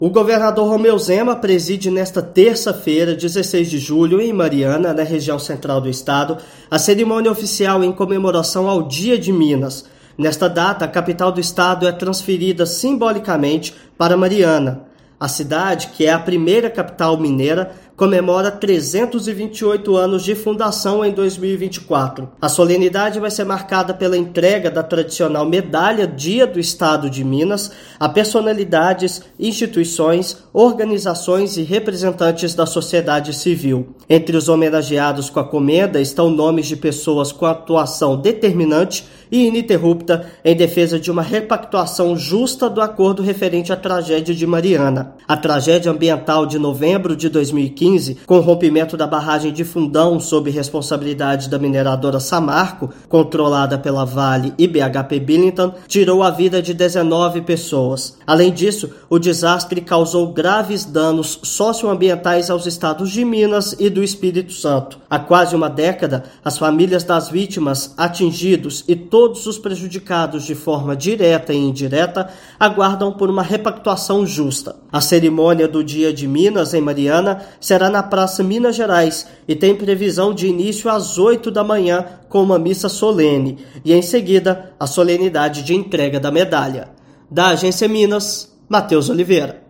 Cerimônia oficial na primeira capital do estado reforça cobrança do Poder Público para repactuação justa do acordo judicial aguardado há quase uma década. Ouça matéria de rádio.